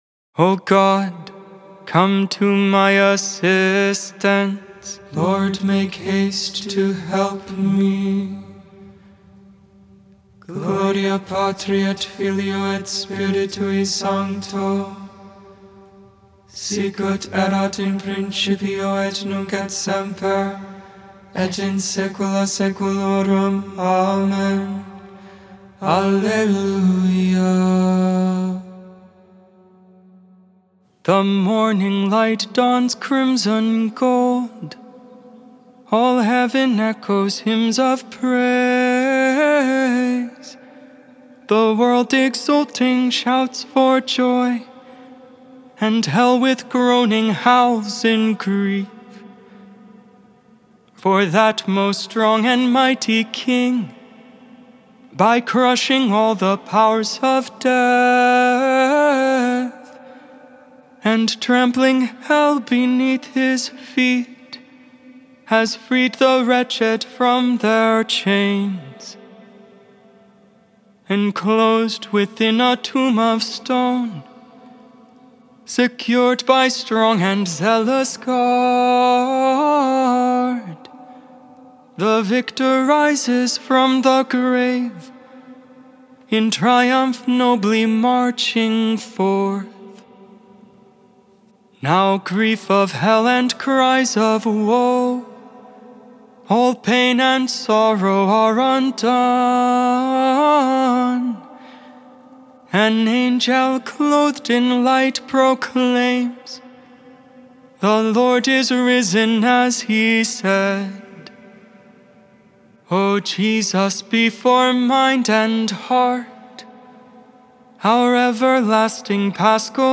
Music, Christianity, Religion & Spirituality